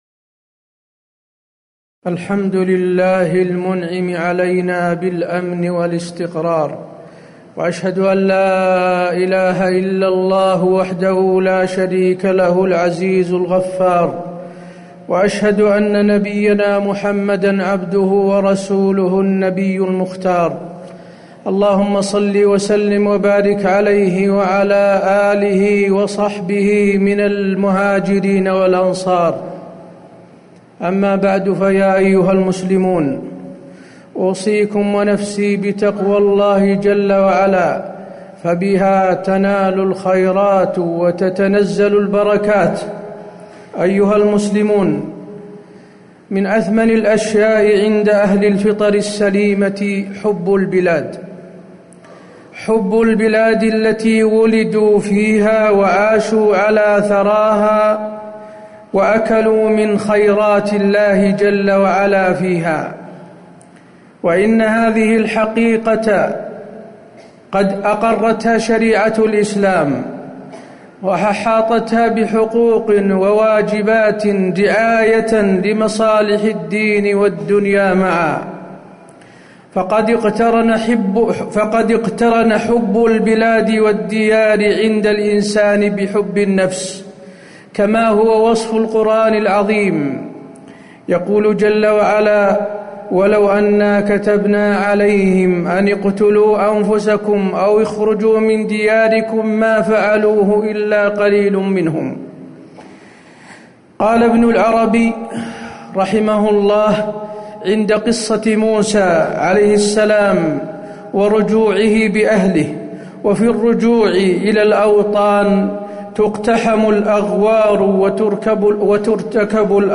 تاريخ النشر ٣٠ محرم ١٤٣٩ هـ المكان: المسجد النبوي الشيخ: فضيلة الشيخ د. حسين بن عبدالعزيز آل الشيخ فضيلة الشيخ د. حسين بن عبدالعزيز آل الشيخ حب البلاد والديار جبلي فطري